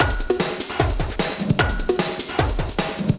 TEKKNO LOOP 1.wav